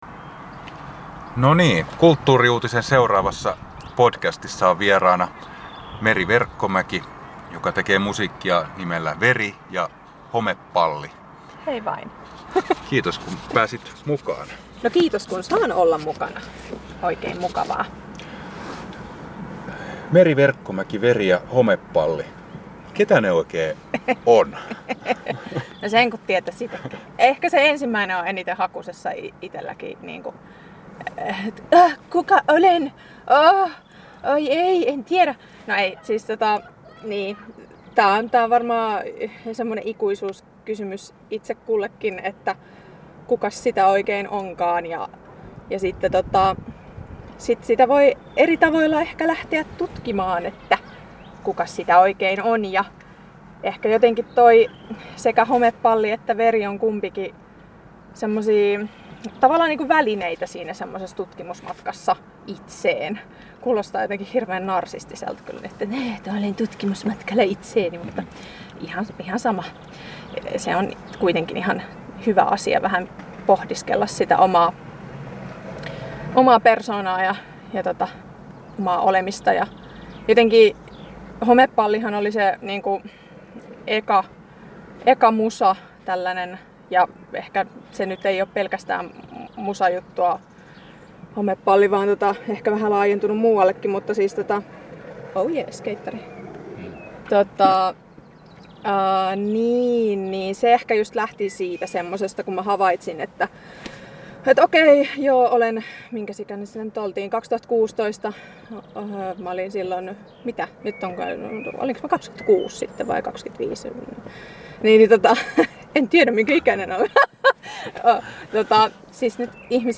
alkaa eläväinen ja innostunut keskustelu.